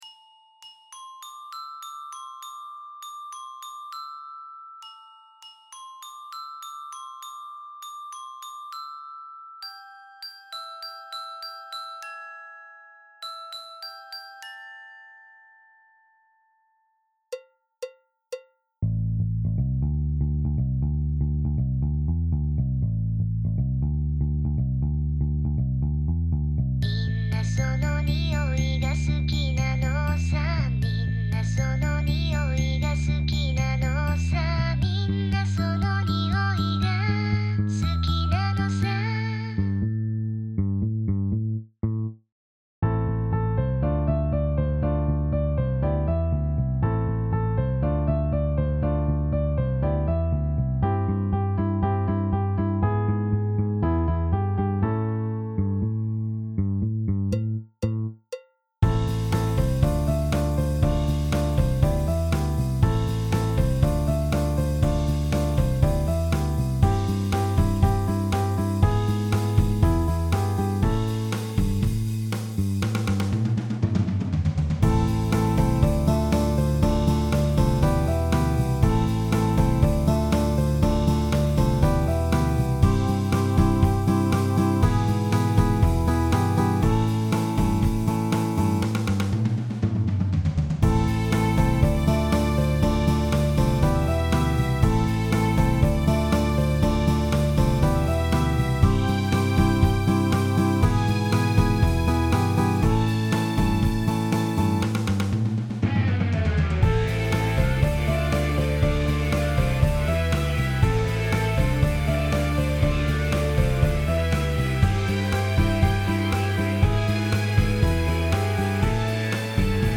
저는 음성 합성 소프트웨어를 사용하여 노래의 일부 섹션에 보컬을 통합했습니다.